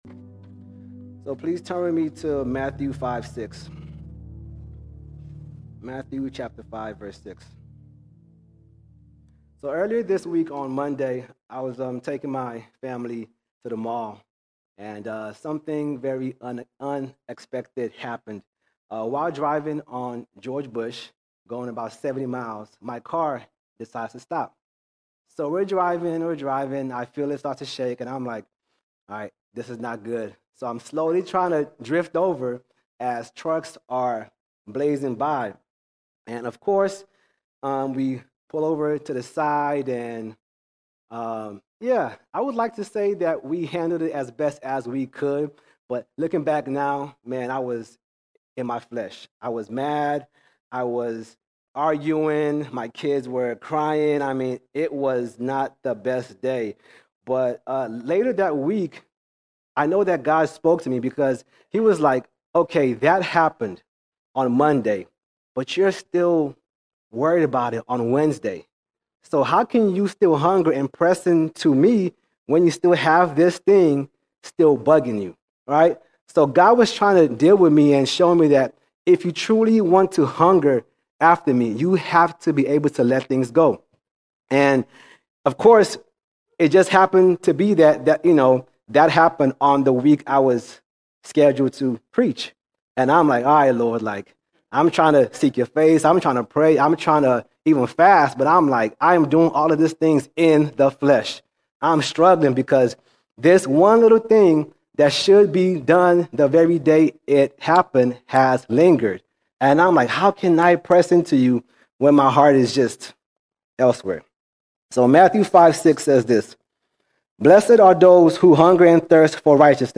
24 February 2025 Series: Sunday Sermons All Sermons Hunger Games Hunger Games True hunger for God is revealed not just in what we know, but in where we dwell, what we behold, and what we seek in prayer.